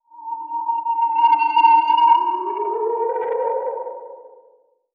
Magic_1_1.wav